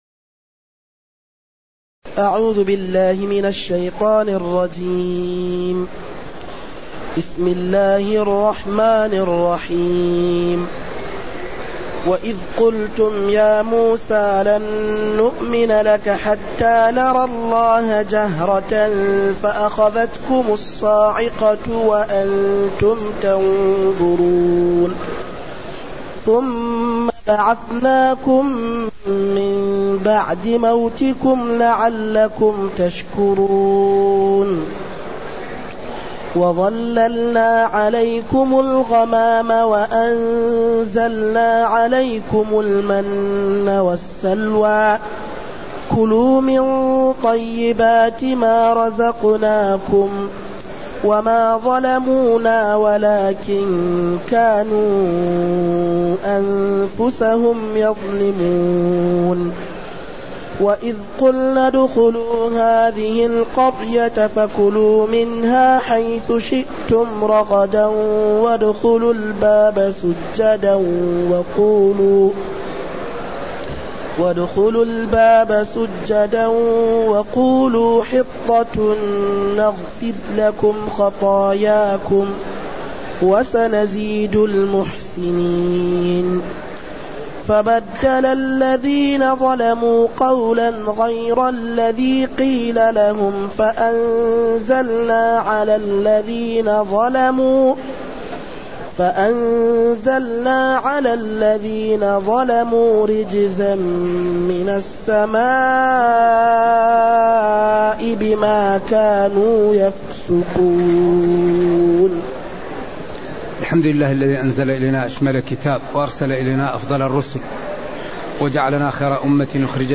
تاريخ النشر ٨ محرم ١٤٢٨ هـ المكان: المسجد النبوي الشيخ